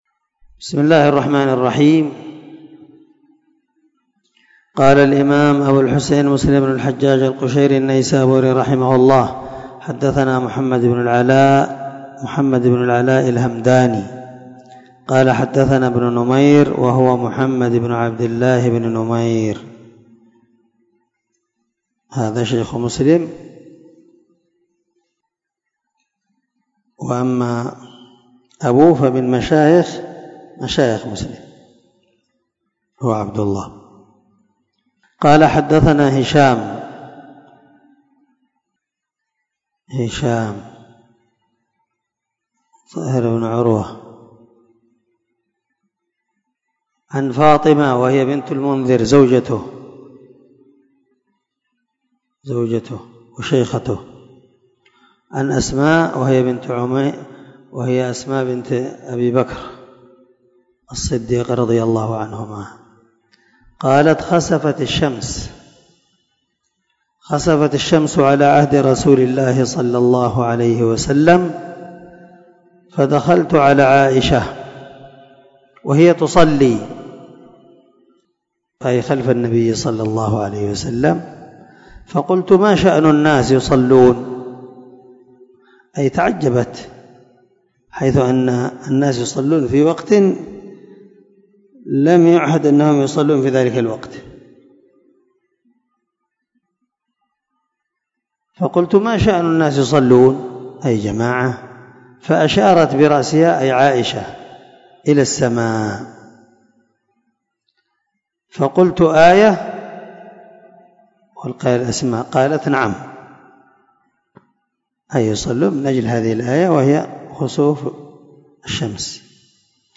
552الدرس 4 من شرح كتاب الكسوف رقم( 905_ 906) من صحيح مسلم